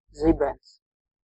Ääntäminen
Ääntäminen France: IPA: [fudʁ] Haettu sana löytyi näillä lähdekielillä: ranska Käännös Ääninäyte 1. zibens {m} Suku: f .